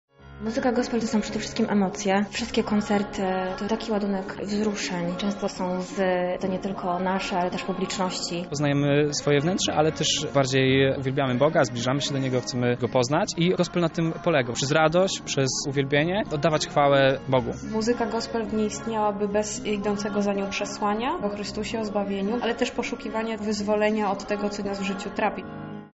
Tłumaczą sami uczestnicy